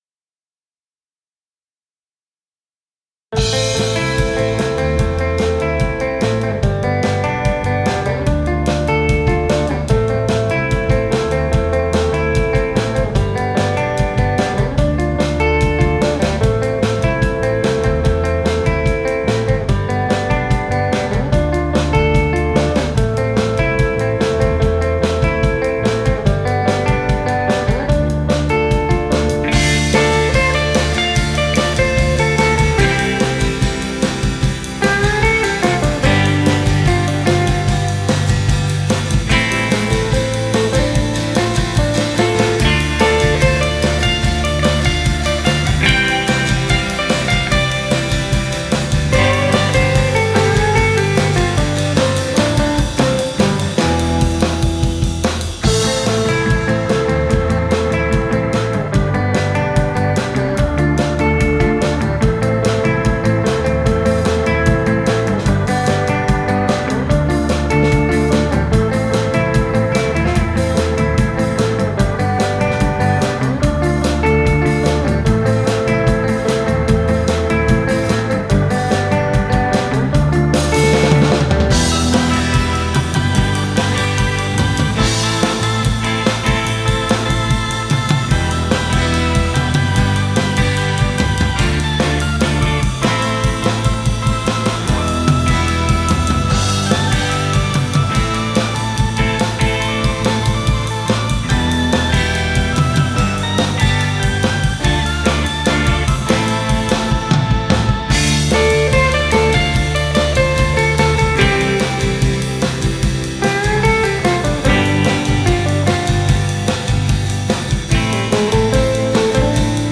This a little song I wrote after being inspired by a bunch of surf rock.
This was recorded live in my basement and then I added keys and acoustic guitar plus I had the other guy re-track his rhythm guitar. I am playing guitar and acoustic guitar. Other than that there is another guitarist, bass, drums, and keys.
I use an RE20 on the guitars ---> FMR RNP ---> Digi 002.
-Just a little EQ, stereo widening, exciting, and limiting. On drums it's a D6 on bass drum, SM57s on the snare, tom1 and floor tom, and a pair of Naiant SDCs as overheads in a recorder man position.